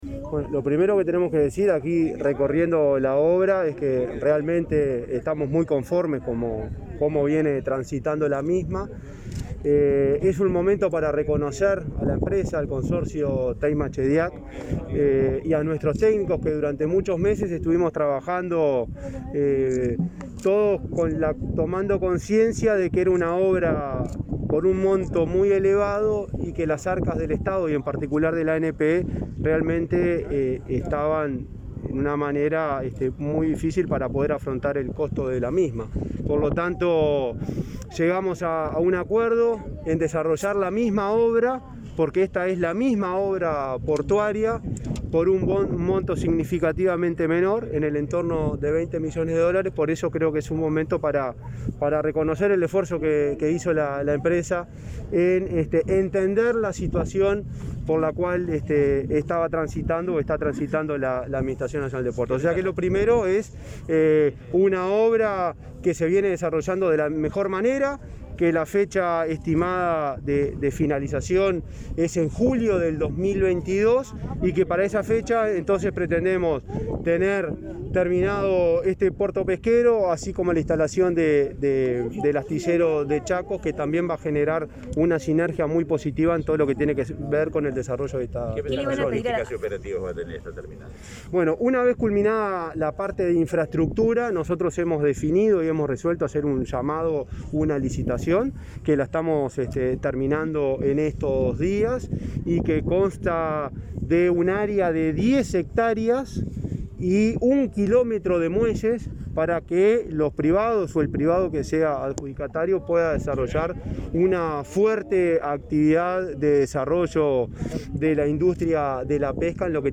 Palabras del presidente de la ANP, Juan Curbelo, en la recorrida por la obra del puerto pesquero de Capurro
“Estamos muy conformes con el avance de las obrasˮ de construcción del puerto Capurro por parte del consorcio Teyma-Chediack, afirmó el presidente de la Administración Nacional de Puertos (ANP), Juan Curbelo, en declaraciones a la prensa, este jueves 10, durante la visita técnica a ese punto ubicado al oeste de la bahía de Montevideo.